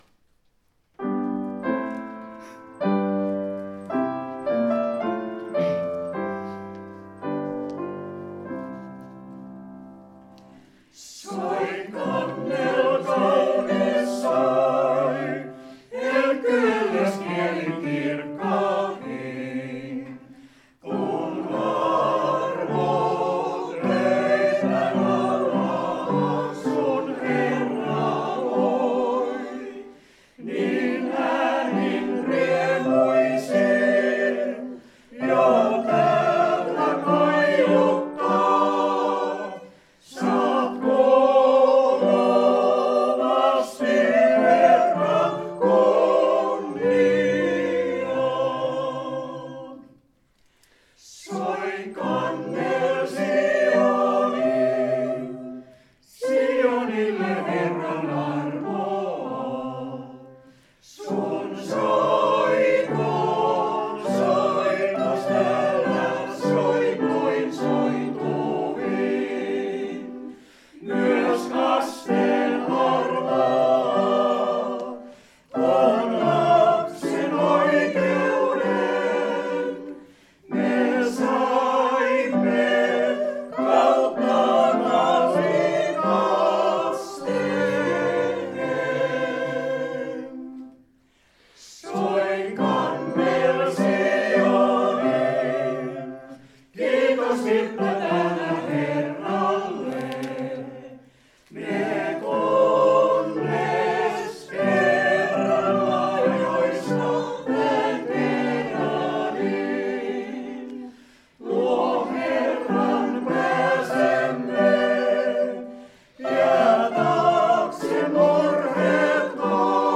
Jalasjärven srk-koti